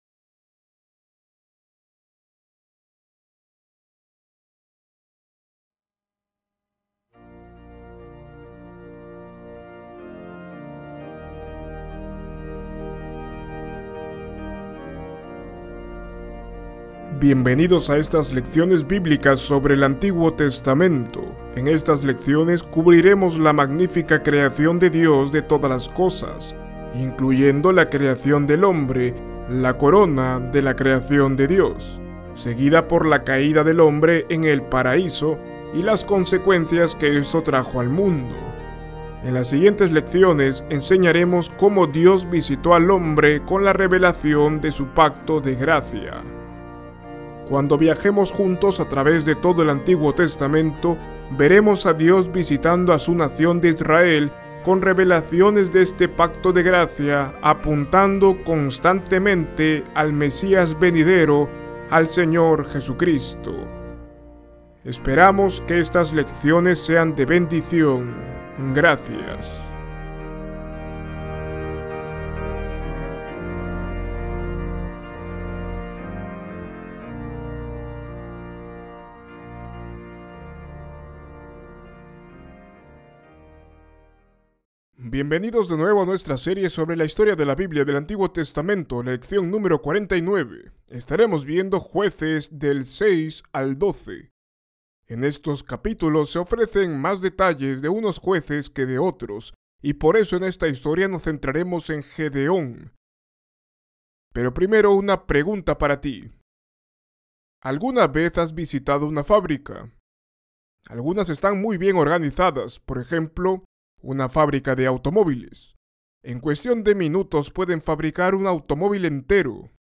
En Su misericordia, Dios levantó a Gedeón para liberar a los israelitas de la tiranía de sus enemigos. Esta lección nos cuenta la historia de Gedeón y su gran victoria para el pueblo de Dios.